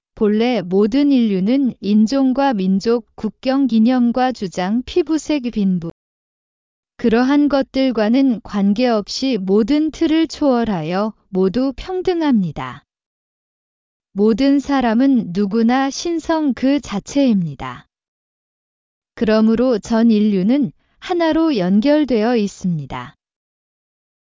本来の在り方宣言ー韓国語(女性).mp3